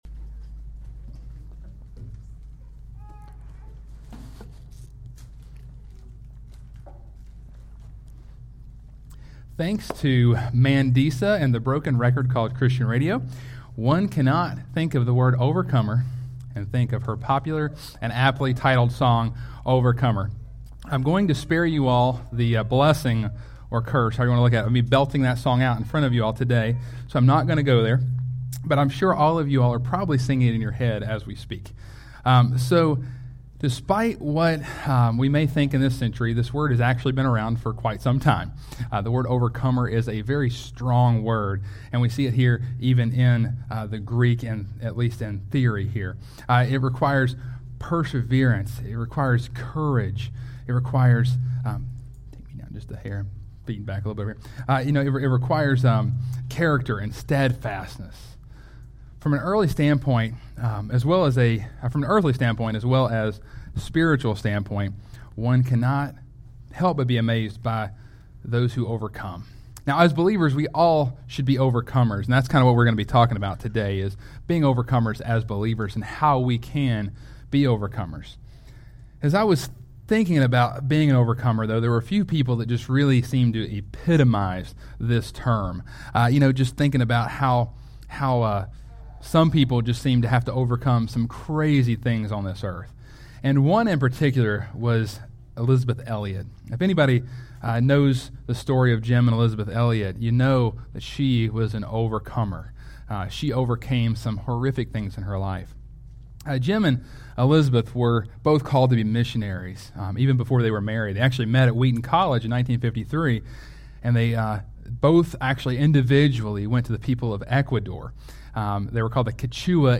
Sermons | CrossPointe Family Church